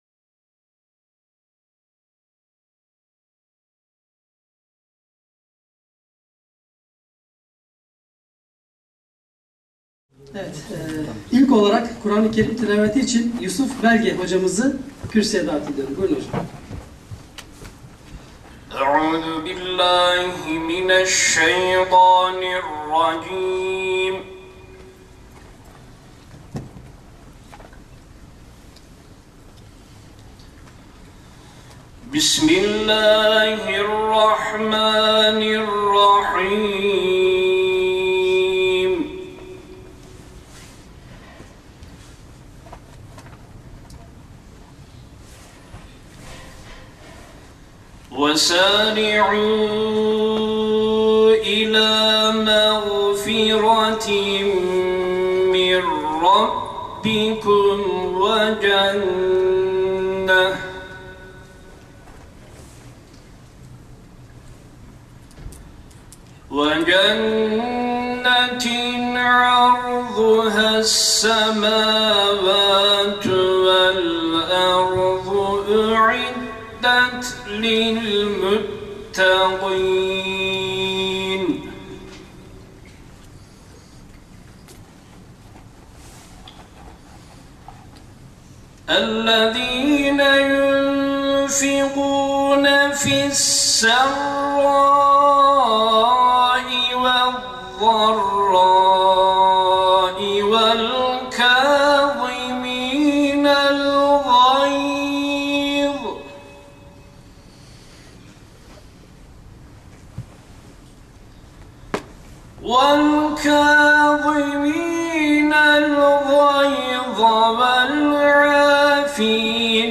Konferanslar